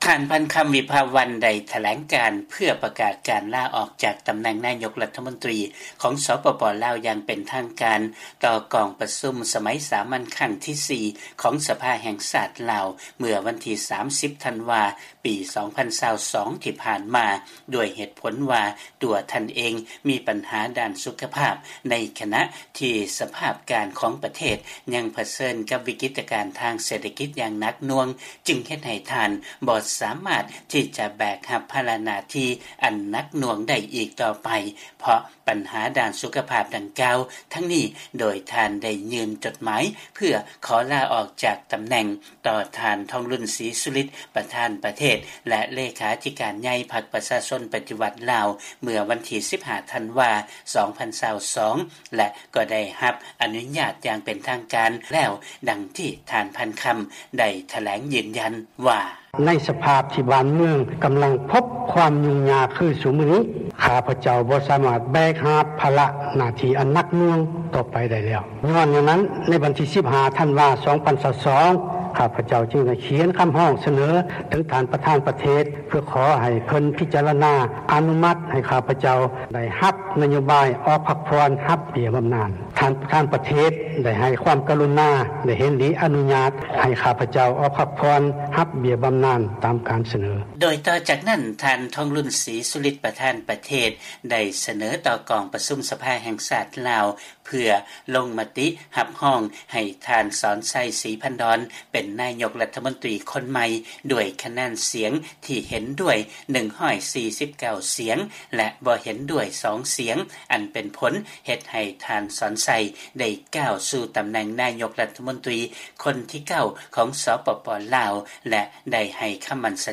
ເຊີນຟັງລາຍງານກ່ຽວກັບການລາອອກຂອງທ່ານພັນຄຳ ວິພາວັນ ແລະການກ່າວຄຳປາໄສຂອງທ່ານສອນໄຊ ສີພັນດອນ, ນາຍົກໃໝ່ຂອງລາວ